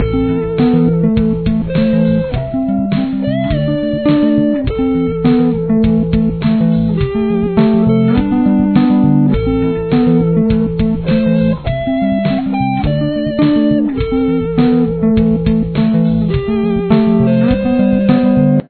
Here’s what they sound like together: